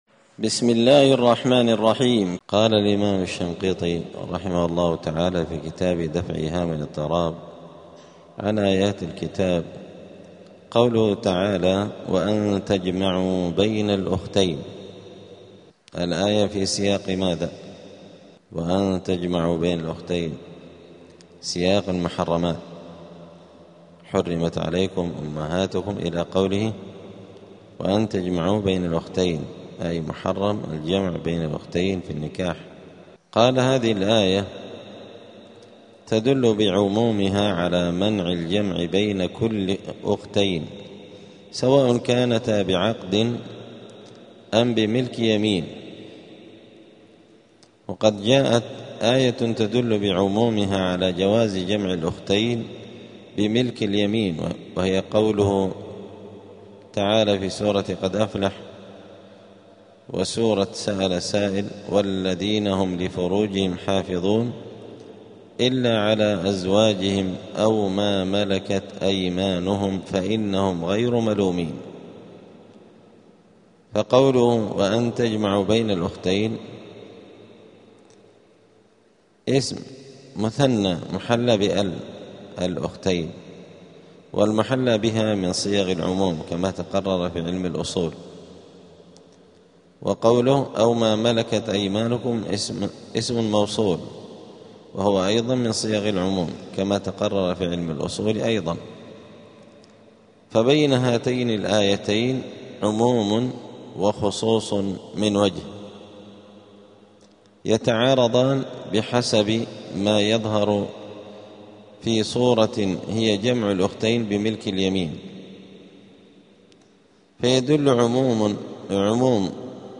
*الدرس الثاني العشرون (22) {سورة النساء}.*